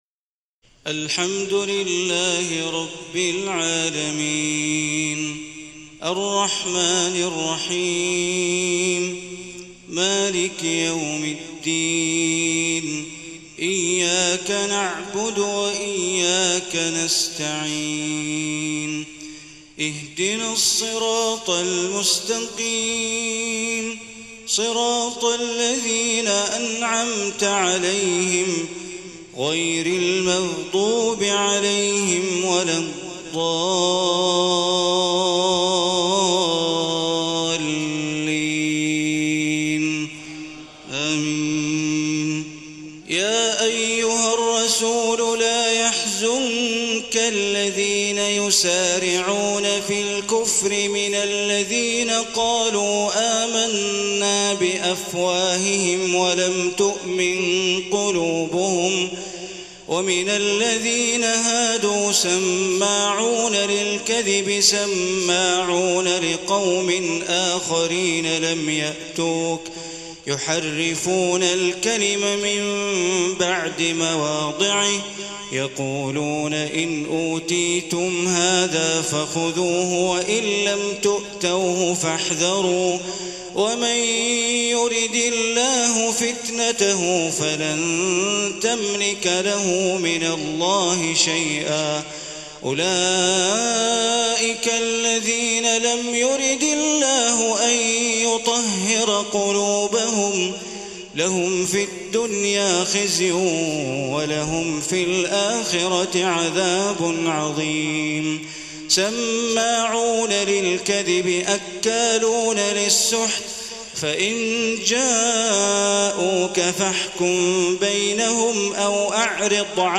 تهجد ليلة 26 رمضان 1434هـ من سورة المائدة (41-81) Tahajjud 26 st night Ramadan 1434H from Surah AlMa'idah > تراويح الحرم المكي عام 1434 🕋 > التراويح - تلاوات الحرمين